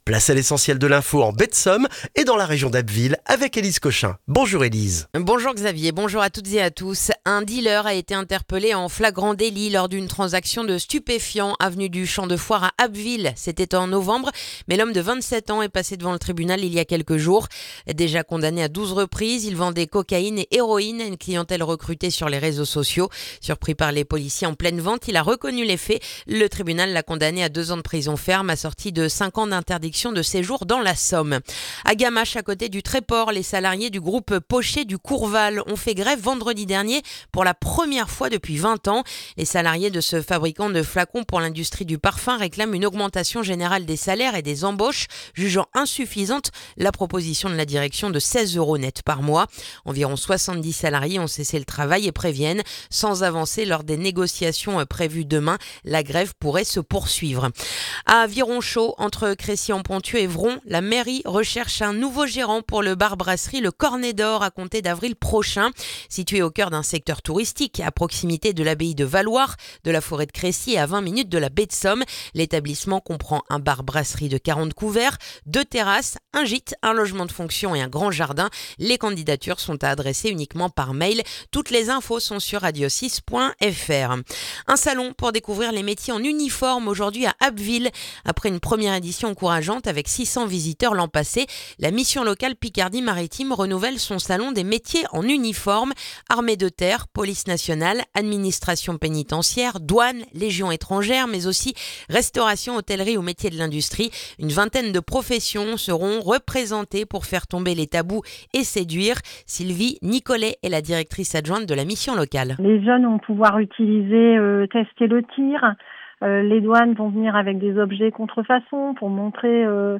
Le journal du mardi 3 février en Baie de Somme et dans la région d'Abbeville